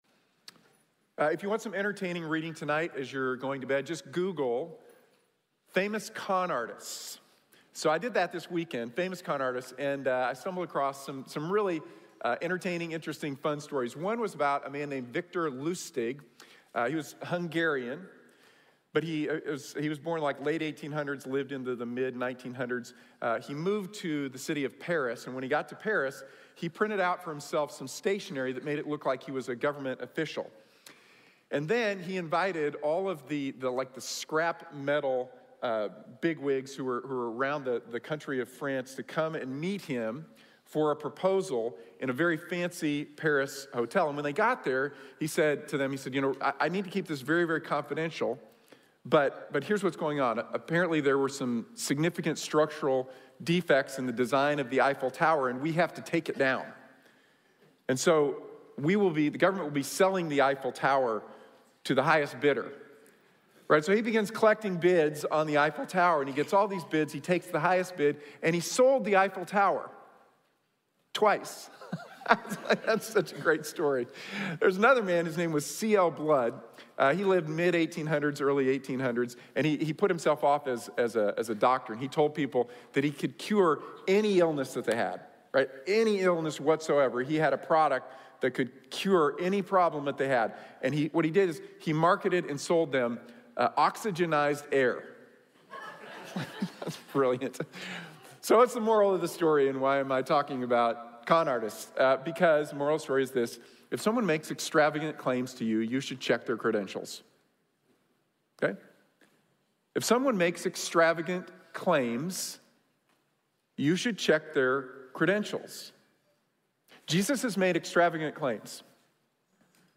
Put Jesus First | Sermon | Grace Bible Church